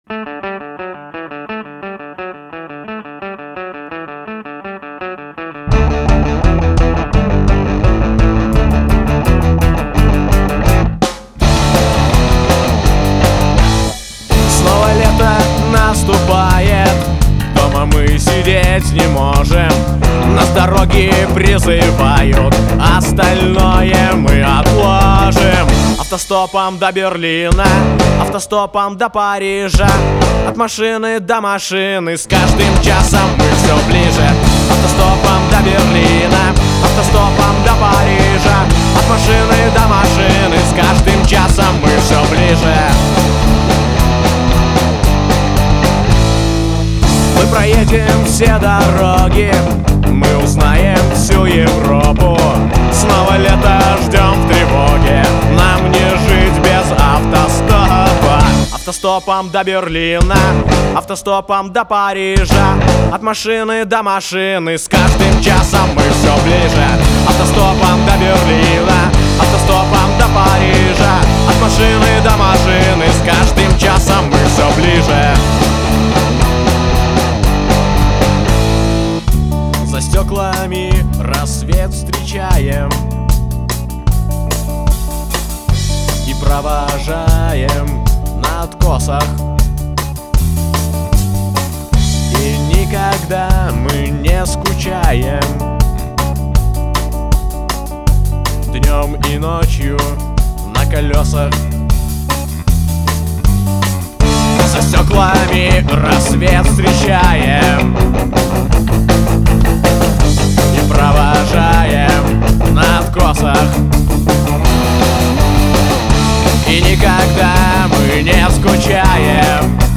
вокал
гитара
ударные
бас
саксофон